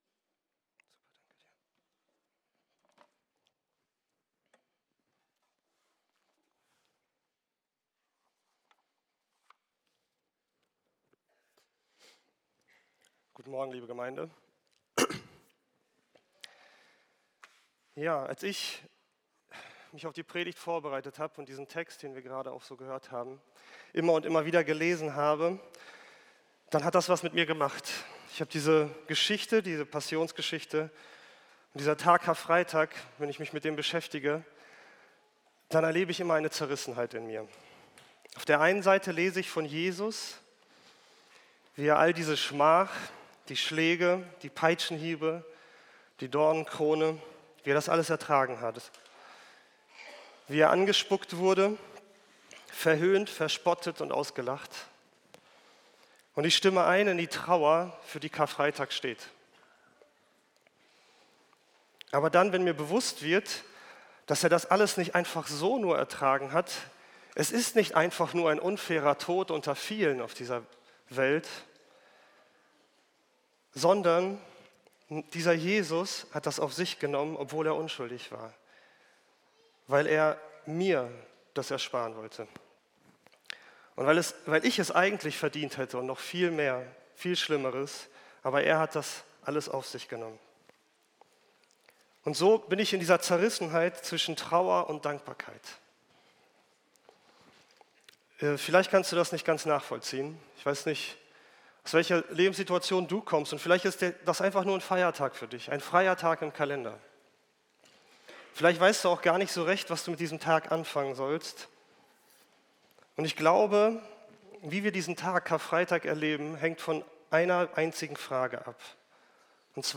Predigten der Gemeinde